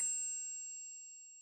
Ding Ding Small Bell
Bell Cartoon Ding hotel Ring SFX Small sound effect free sound royalty free Movies & TV